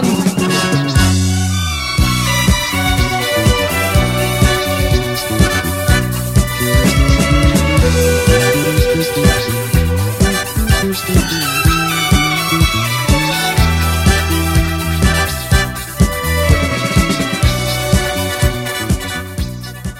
ретро
без слов